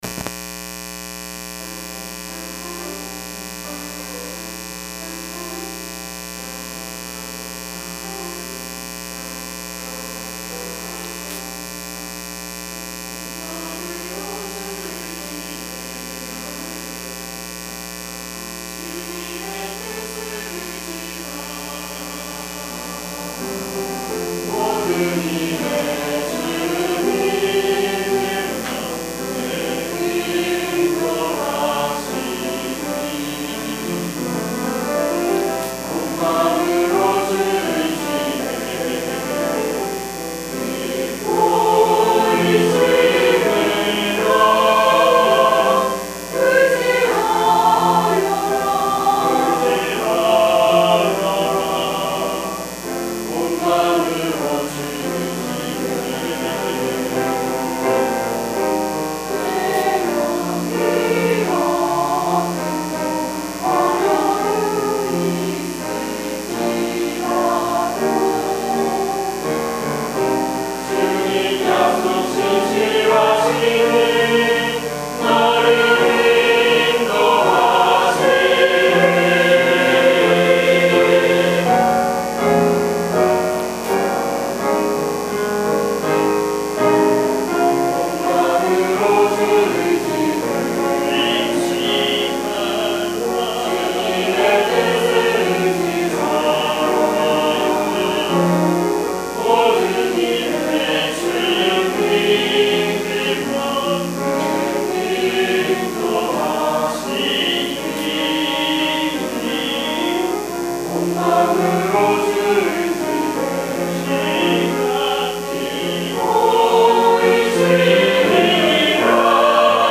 주일찬양